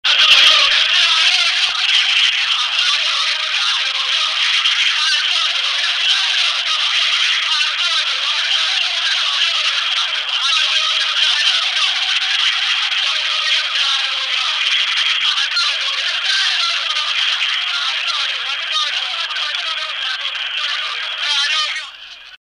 Cori cassano gol.mp3